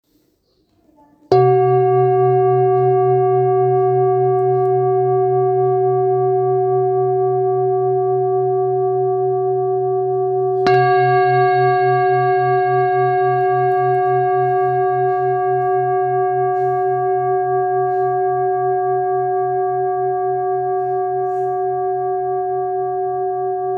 Jambati Singing Bowl Singing Bowl, Buddhist Hand Beaten, Chenrezig Carved
It can discharge an exceptionally low dependable tone.